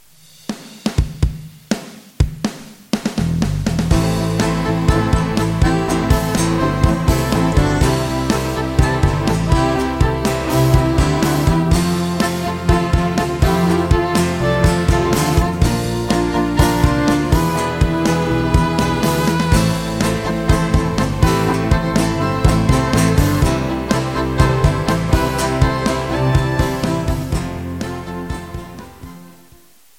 Etude pour Violoncelle